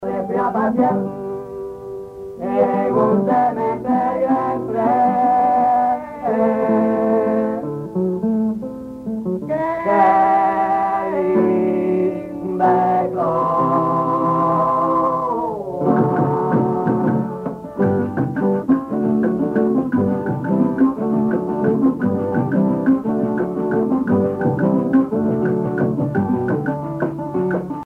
Parranda
Sancti Spiritus, Cuba
Pièce musicale inédite